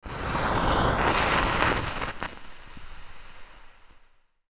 火が燃えるイメージ(マッチ加工) 02
/ F｜演出・アニメ・心理 / F-30 ｜Magic 魔法・特殊効果 /
ジジジC414